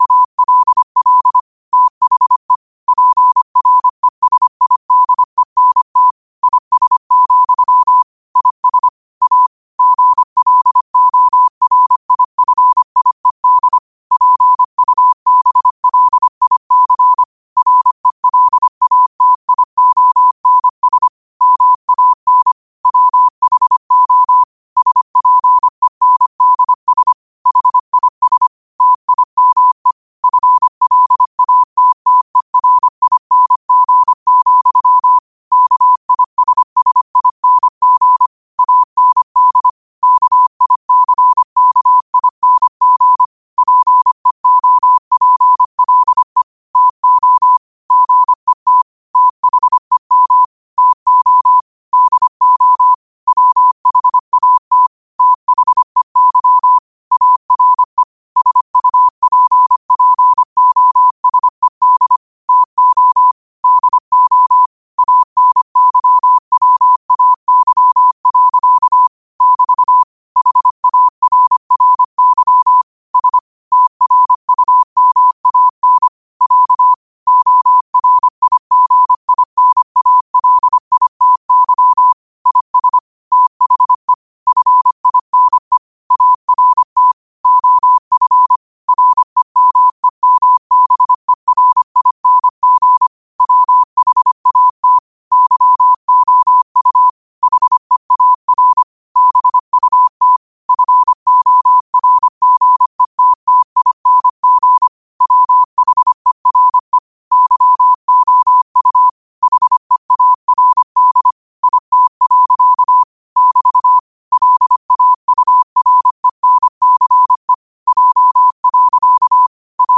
New quotes every day in morse code at 25 Words per minute.